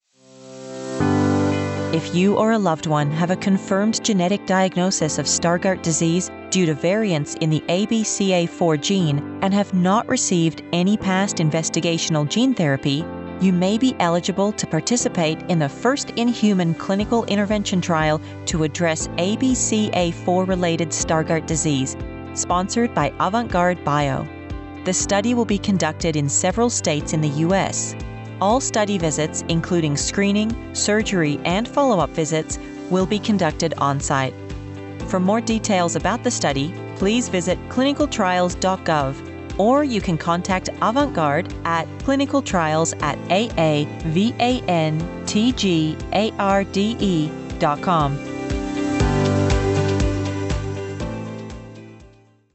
Female
I'm a trained actor with a grounded, connected sound.
Radio P S A For Georgia, Usa
Words that describe my voice are Relatable, Conversational, Versatile.